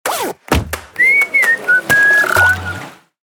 Przykłady znaków towarowych dźwiękowych: